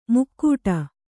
♪ mukkūṭa